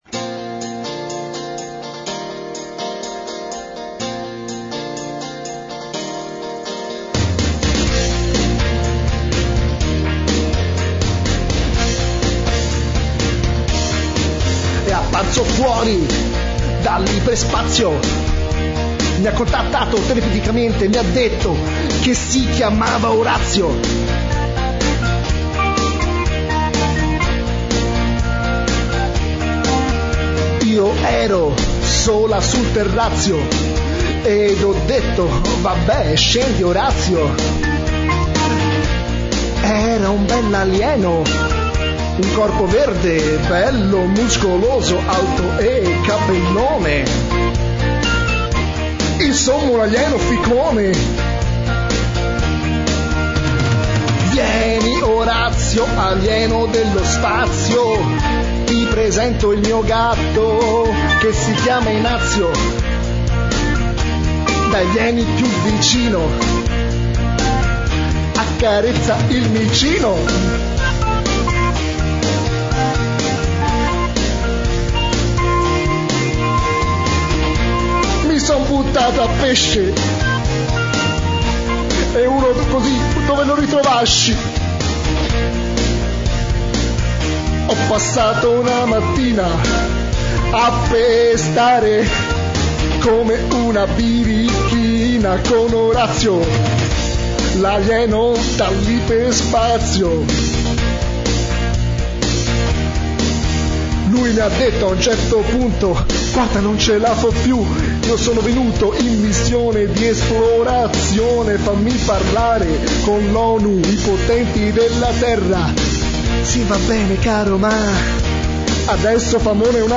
Una canzone improvvisata